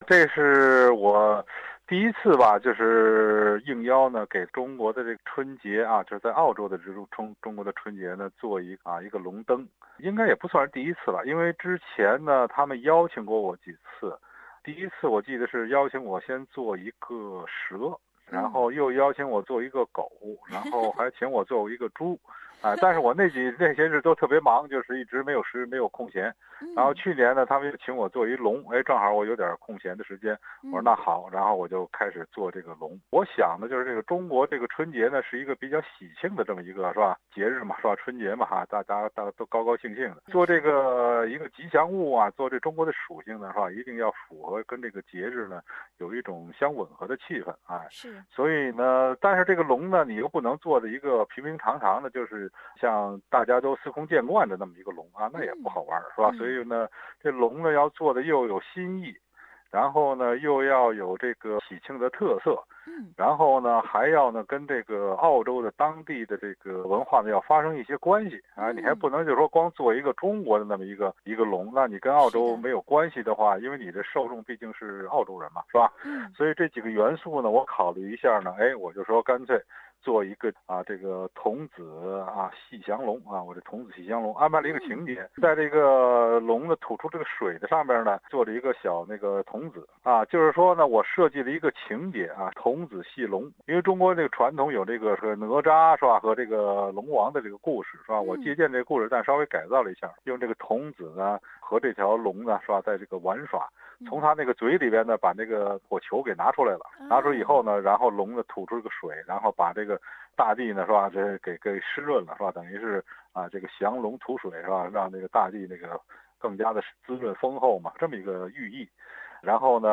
專訪童子戲龍彩燈設計者，著名現噹代藝術家——關偉